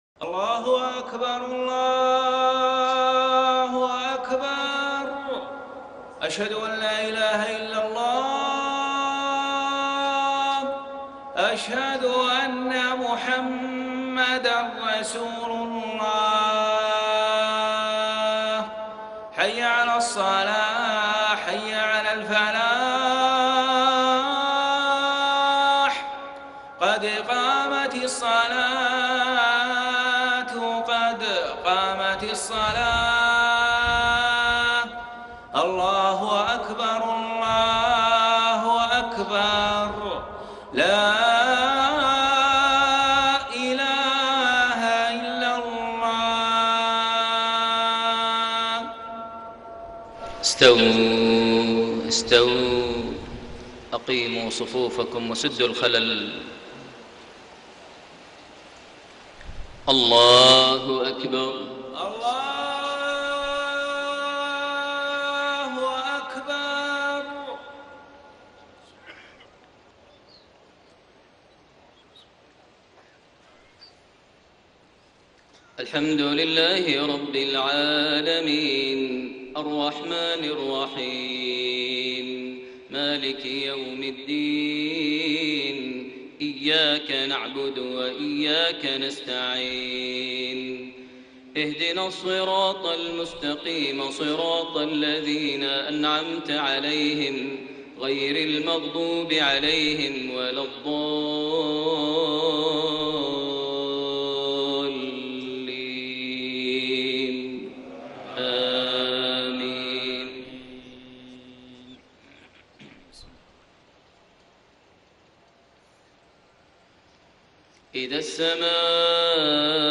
Maghrib prayer from Surah Al-Infitaar > 1433 H > Prayers - Maher Almuaiqly Recitations